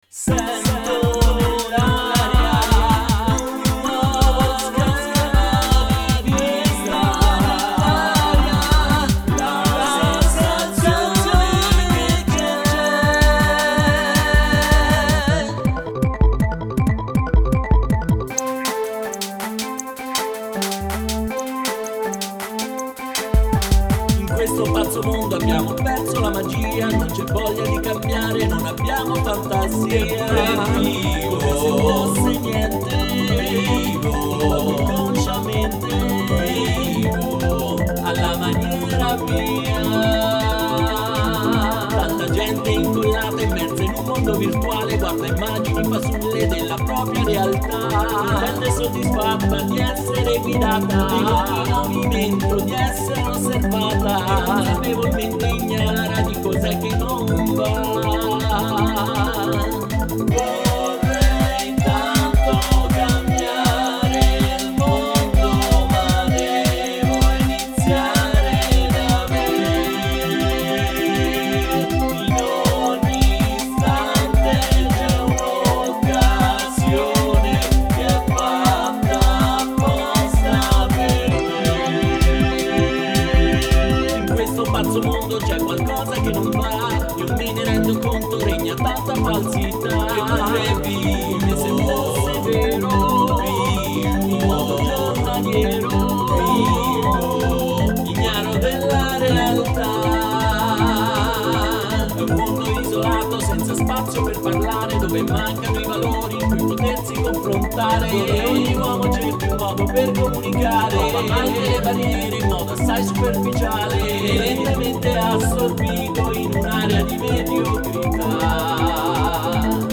voce, cori, tastiere, chitarre e programmazione.